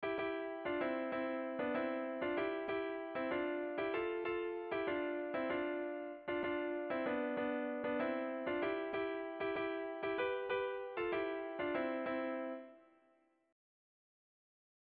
Erromantzea
Lauko handia (hg) / Bi puntuko handia (ip)
AB